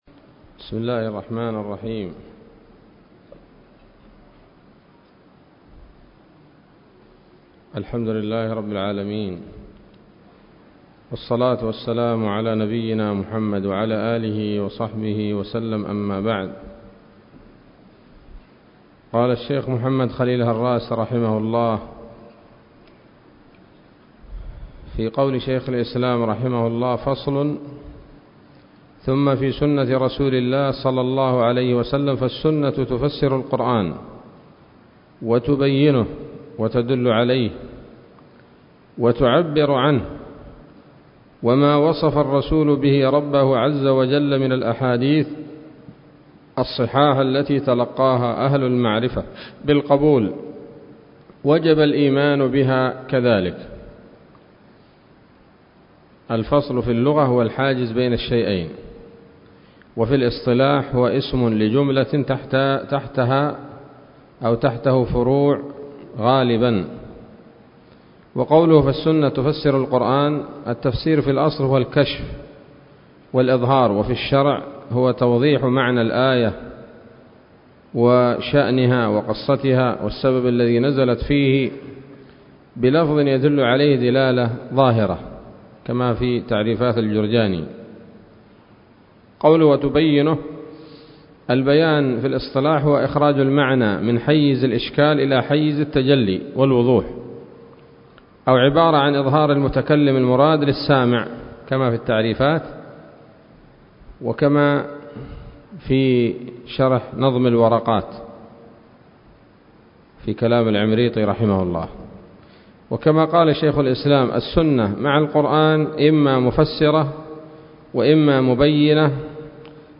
الدرس الثالث والثمانون من شرح العقيدة الواسطية للهراس